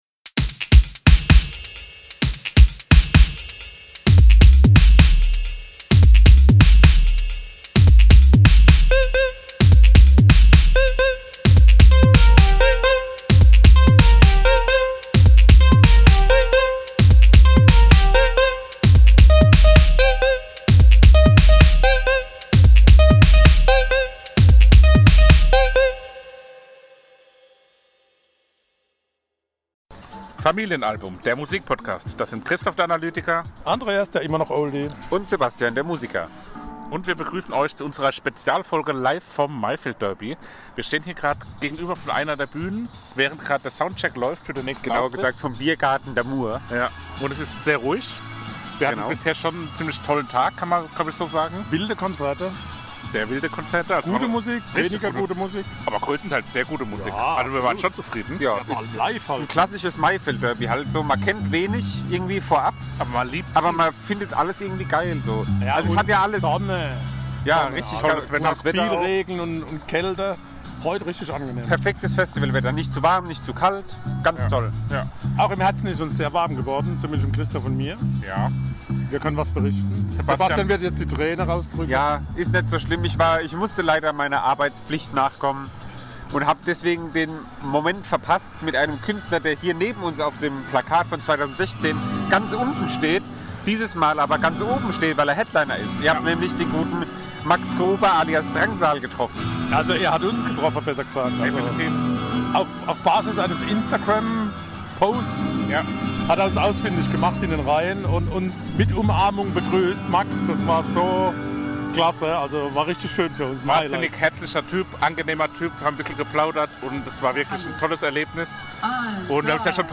Wir melden uns live vom Maifeld Derby!